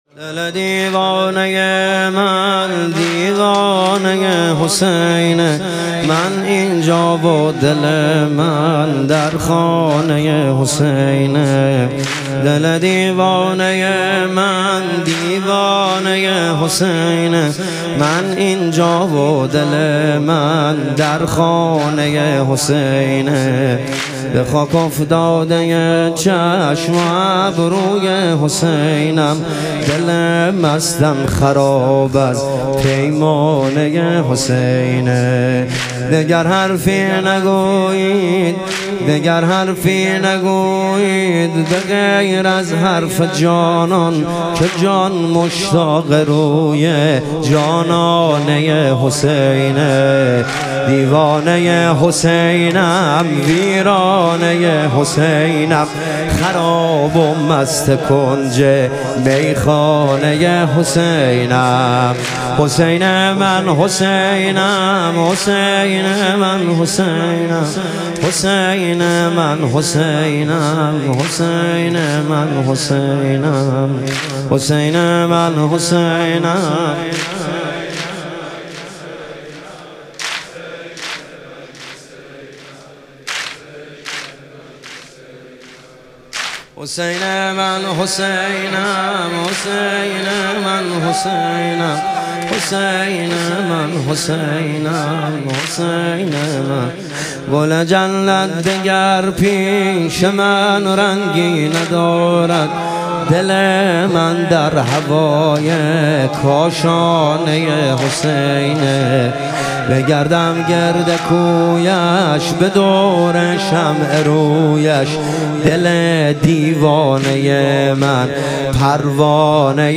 شب شهادت امام هادی علیه السلام